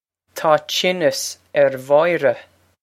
Pronunciation for how to say
Taw cheen-as air Voy-ra.
This is an approximate phonetic pronunciation of the phrase.